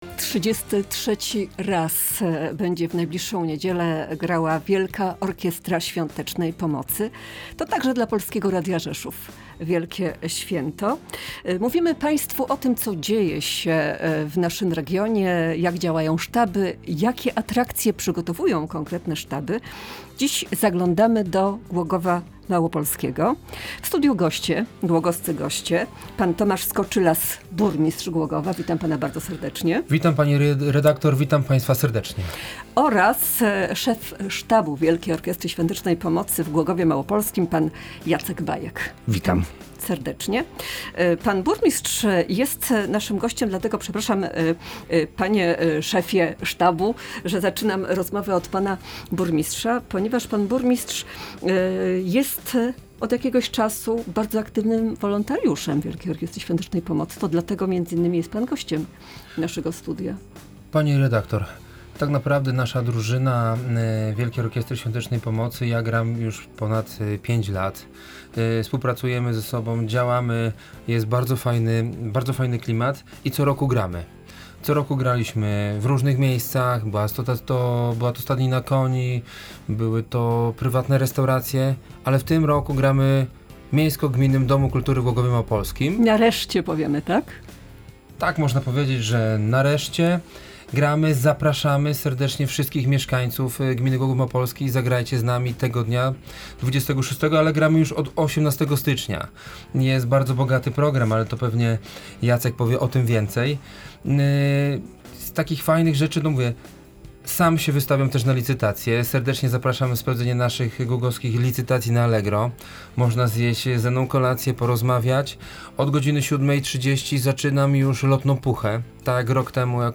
W dzisiejszym programie przedstawiliśmy relacje z Głogowa Małopolskiego i Nowej